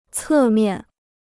侧面 (cè miàn): lateral side; side.